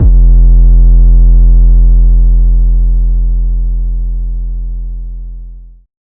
808 Kick 4_DN.wav